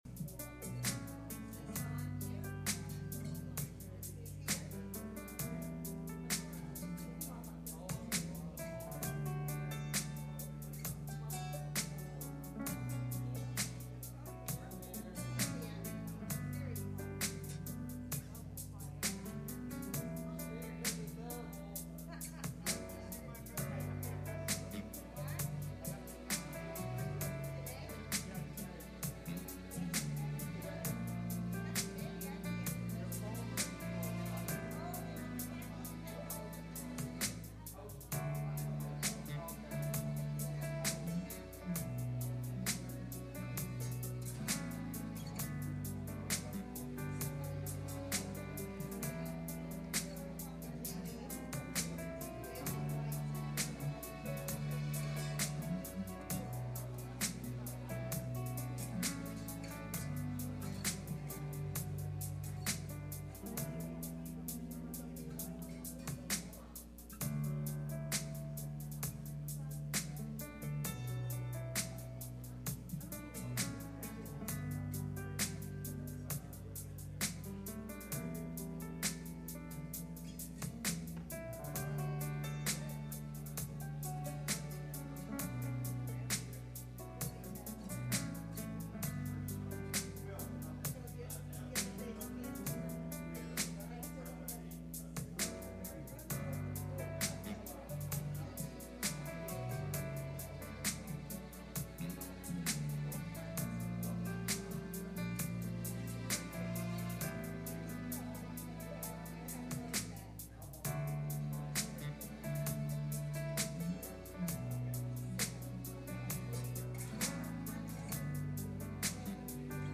Acts 20:7-12 Service Type: Midweek Meeting « Easter Service What’s In Your Hand?